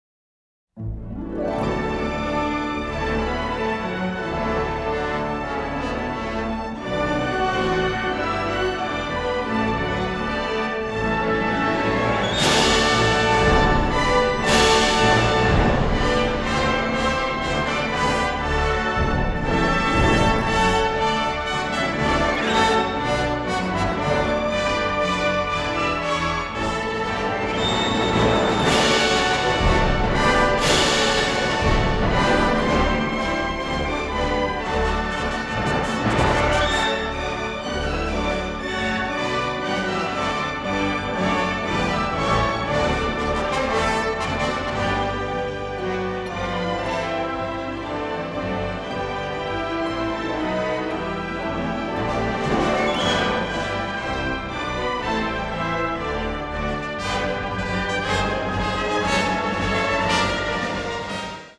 baritone.